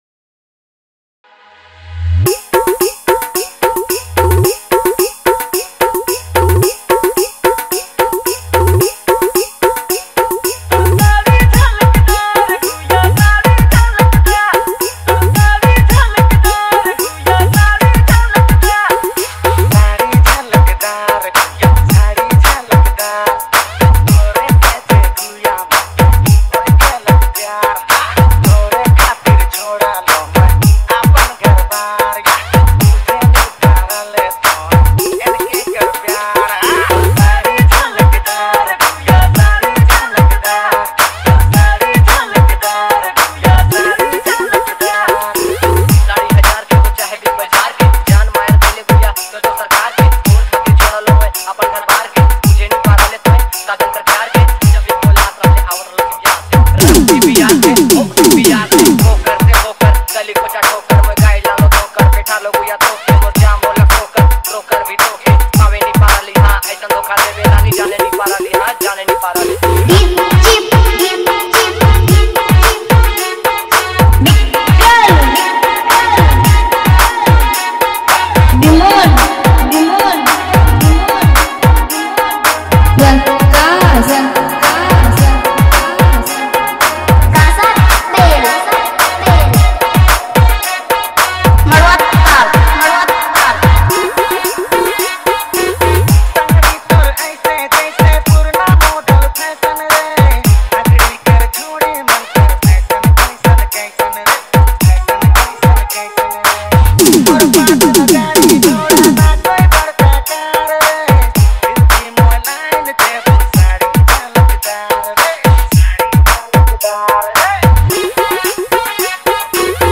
Enjoy the energetic beats
a vibrant CG remix
This 2020 release is perfect for dance lovers.